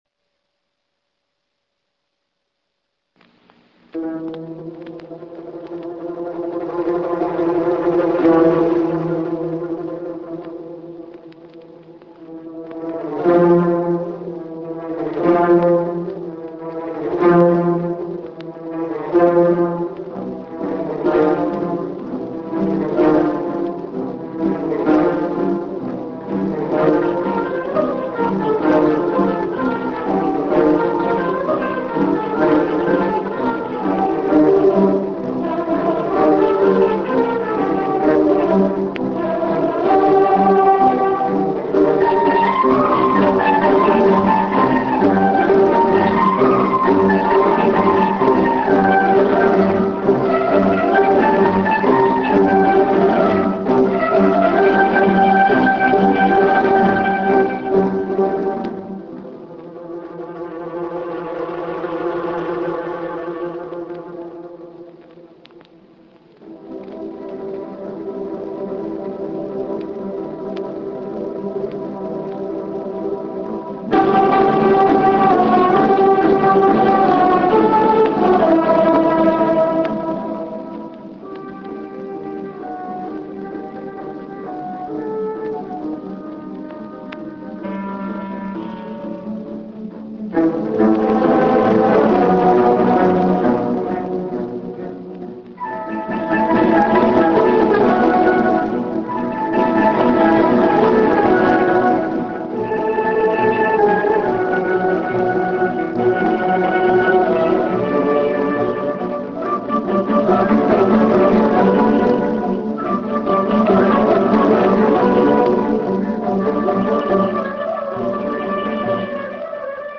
ＫＧＭＣ創立５０周年第４２回定期演奏会
１９６７年１１月２５日 at Festival Hall in OSAKA